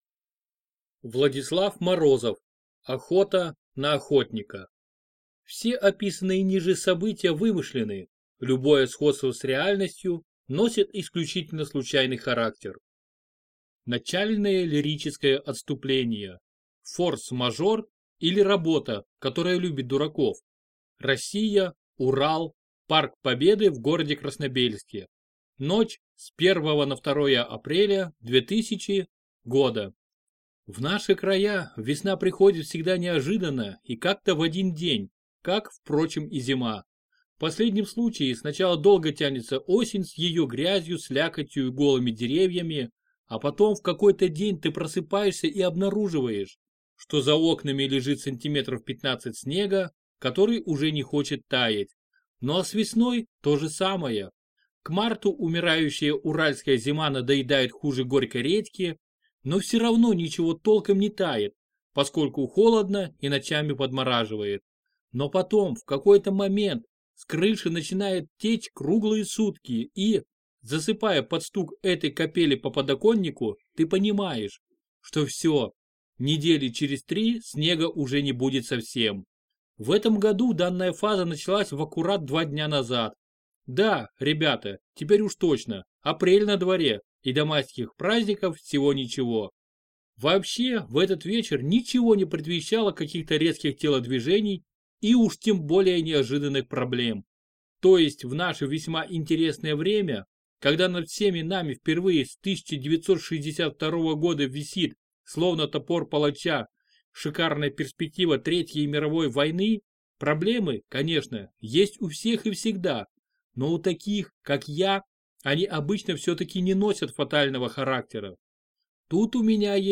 Аудиокнига Охота на охотника | Библиотека аудиокниг